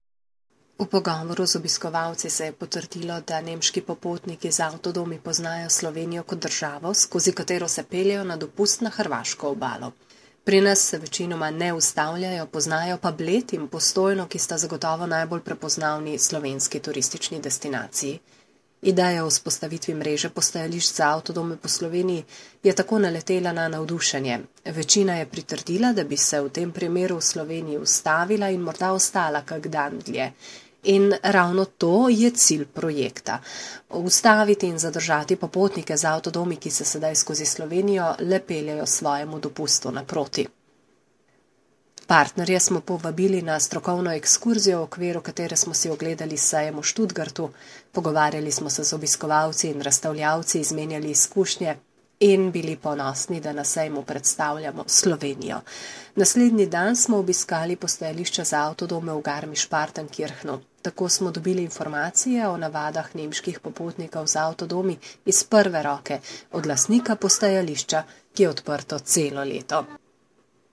(- pripeta tonska izjava